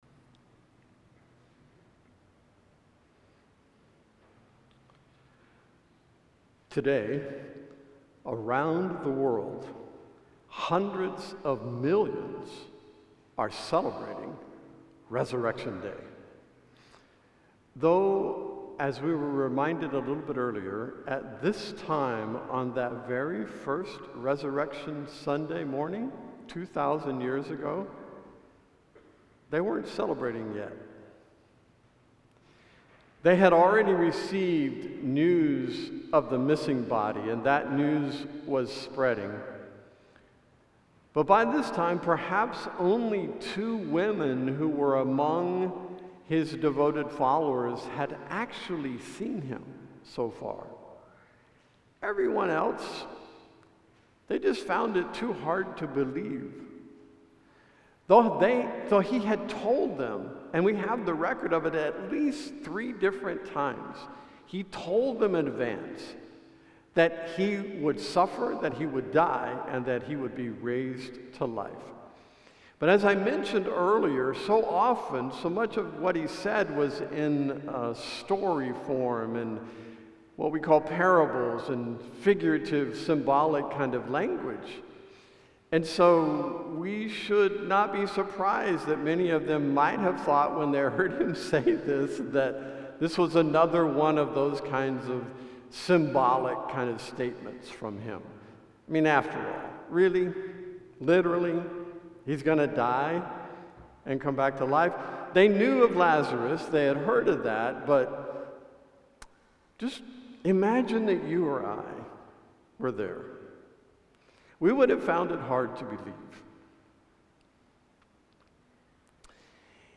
A message from the series "my Shepherd ." John 14 records that Lord Jesus promised an eternal Home for His followers. Psalm 23: 6 eagerly anticipates that promise.